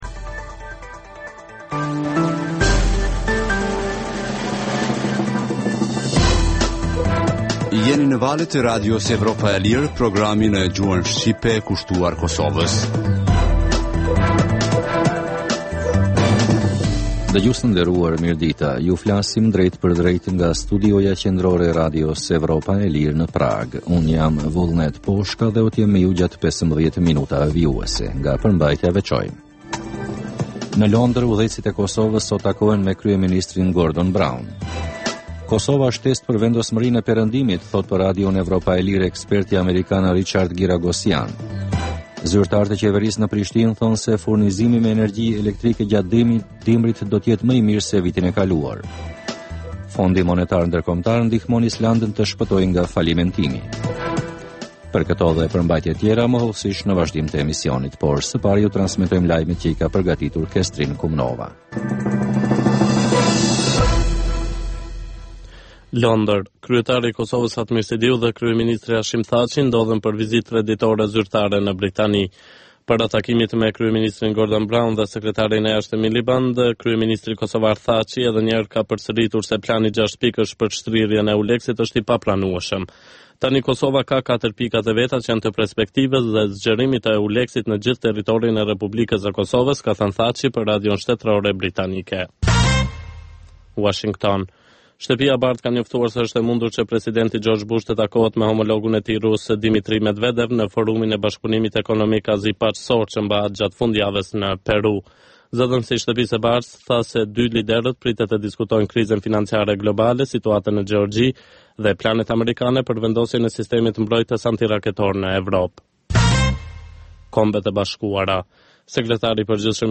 Emisioni i mesditës fillon me buletinin e lajmeve që kanë të bëjnë me zhvillimet e fundit në Kosovë, rajon dhe botë. Në këtë emision sjellim raporte dhe kronika të ditës, por edhe tema aktuale nga zhvillimet politike dhe ekonomike.